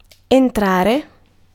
Ääntäminen
IPA: [ɑ̃.tʁe]